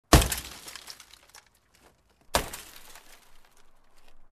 Звуки разбивания машины
На этой странице собрана коллекция реалистичных звуков разбивания автомобиля. Вы можете слушать и скачивать эффекты битья стекол, ударов по металлу кузова и пластику фар.